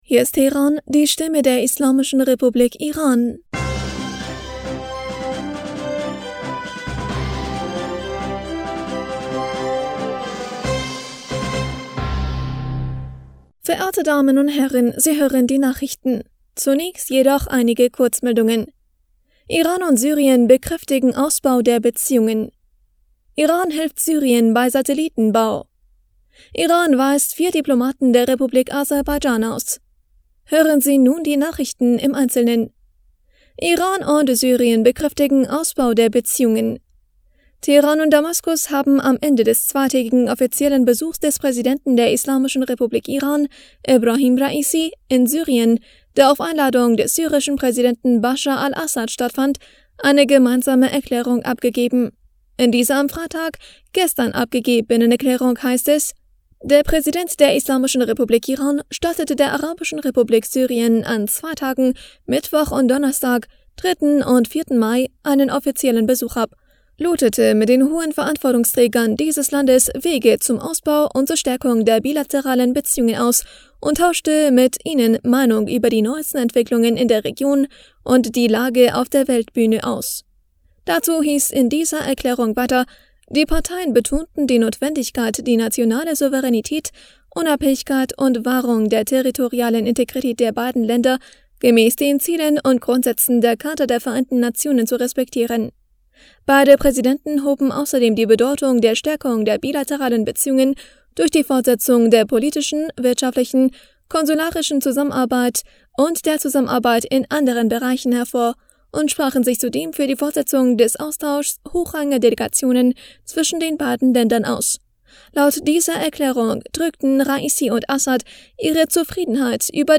Nachrichten vom 6. Mai 2023